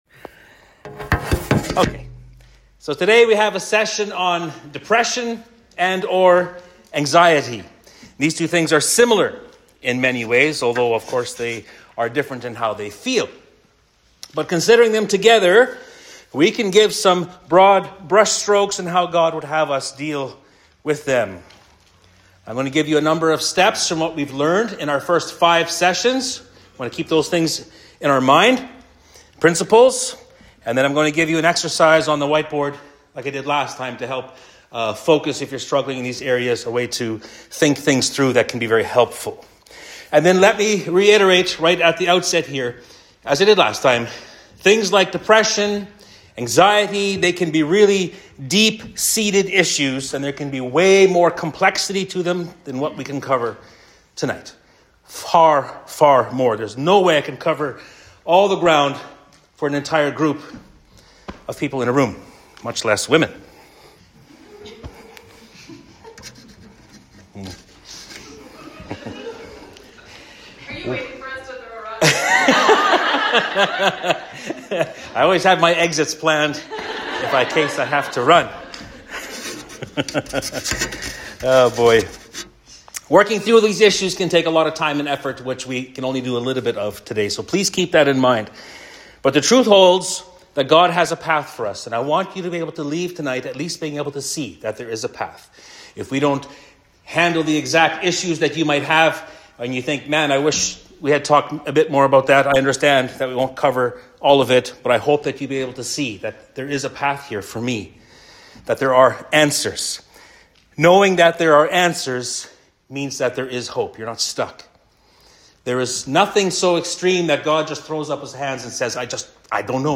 Sermon Notes
Women's Night Sessions